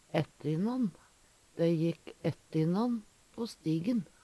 ætte innan - Numedalsmål (en-US)